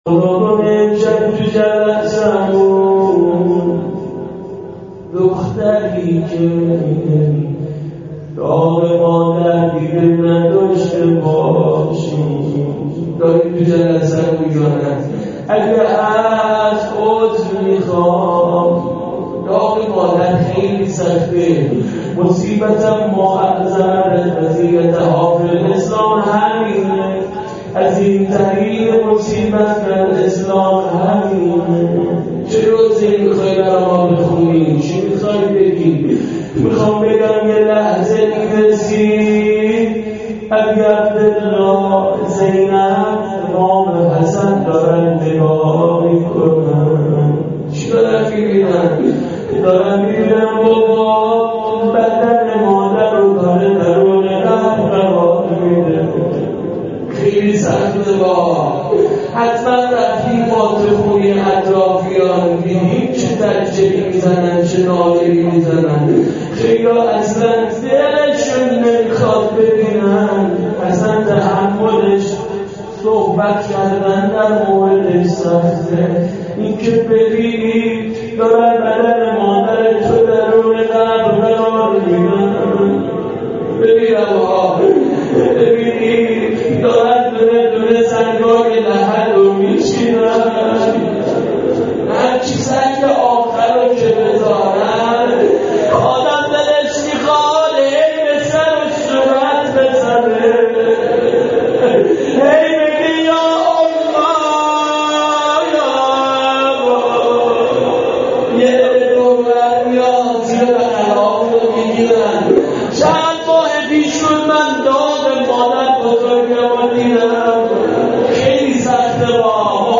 روضه حضرت زهرا.MP3
روضه-حضرت-زهرا.mp3